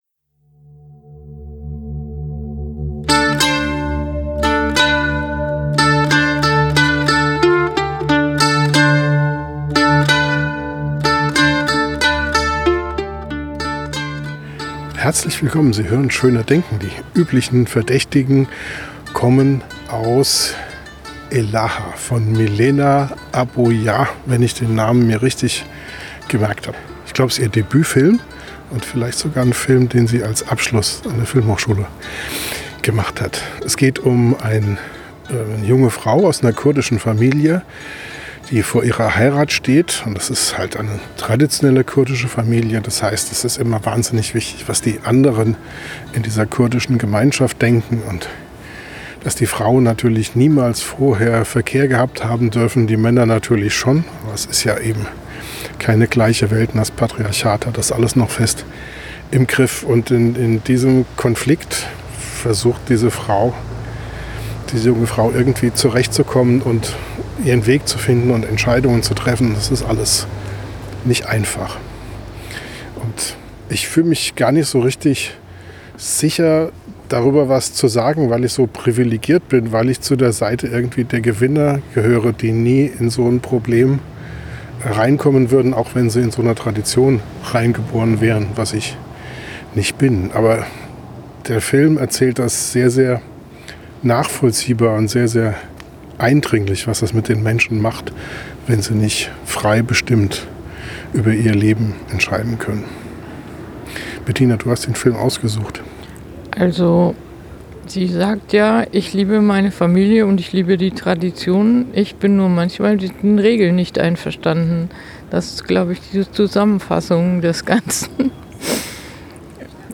Direkt nach dem Kino sind wir im Podcast noch ganz gefangen von den Konflikten und Elahas Ängsten und Hoffnungen.